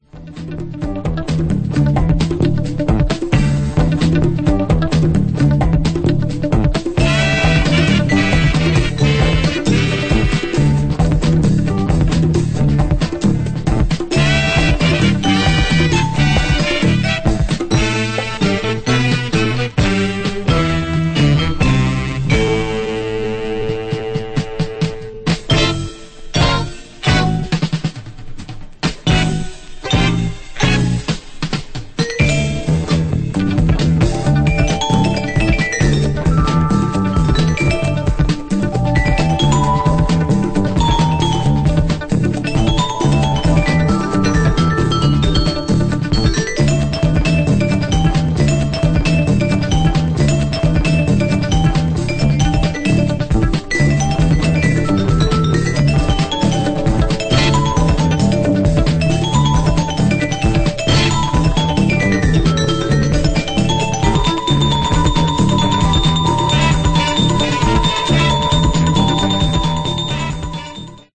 supplier of essential dance music